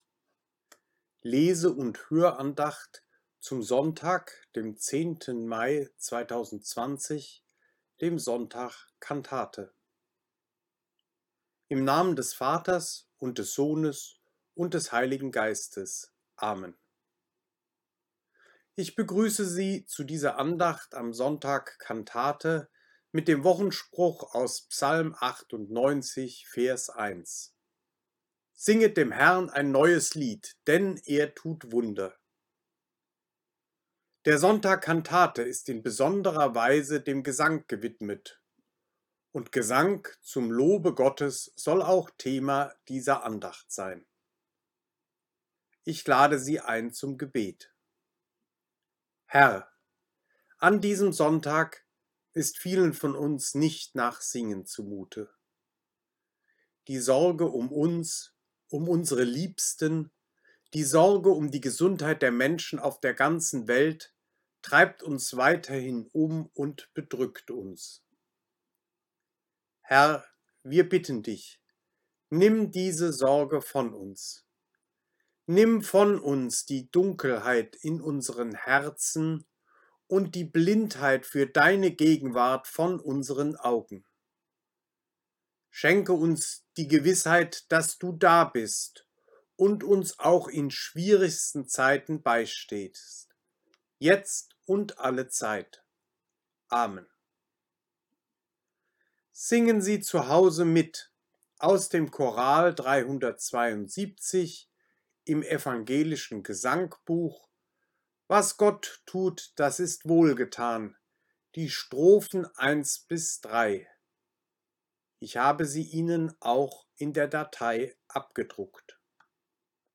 Leseandacht zum Sonntag 10.